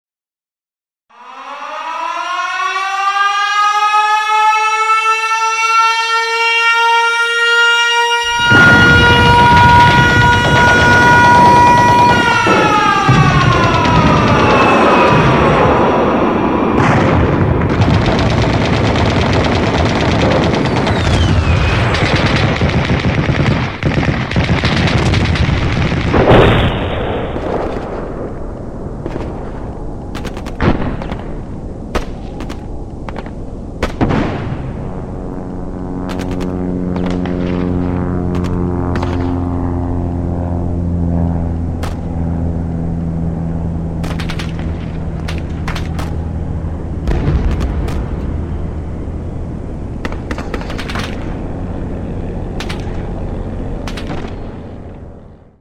На этой странице представлены аудиозаписи, имитирующие звуки выстрелов.
Аудиозаписи боевых звуков Великой Отечественной войны